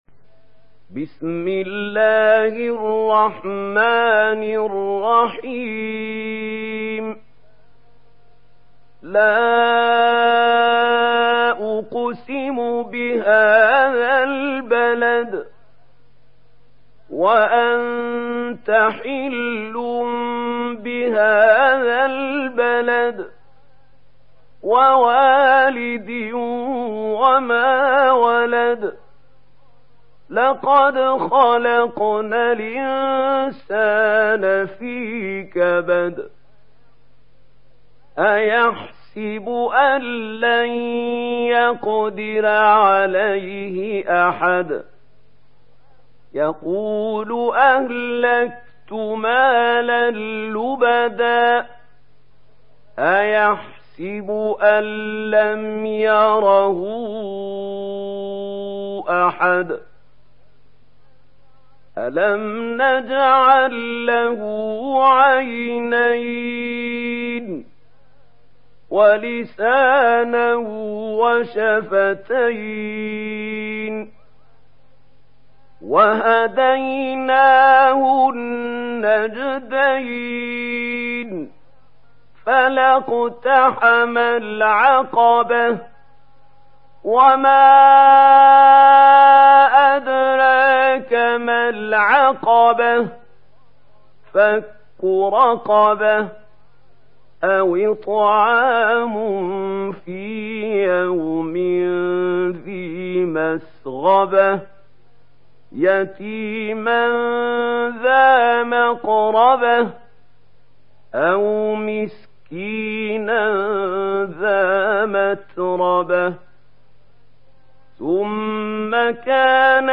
Surah Al Balad Download mp3 Mahmoud Khalil Al Hussary Riwayat Warsh from Nafi, Download Quran and listen mp3 full direct links